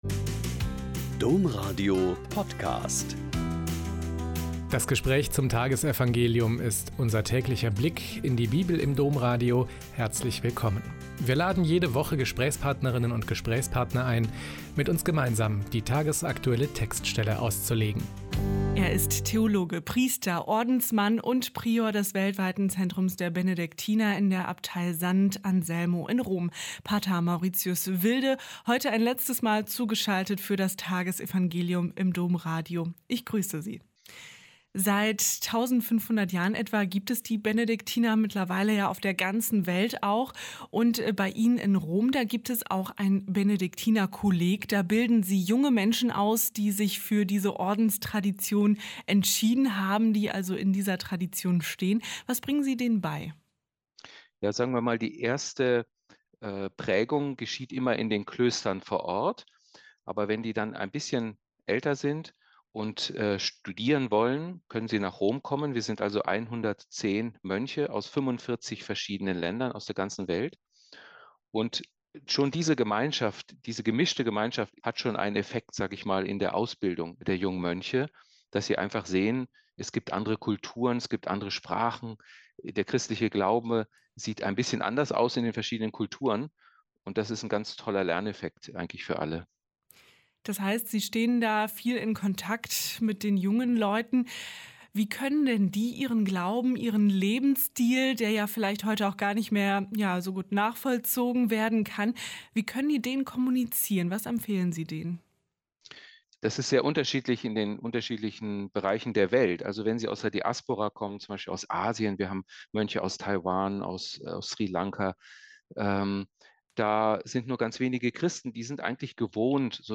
Lk 20,27-40 - Gespräch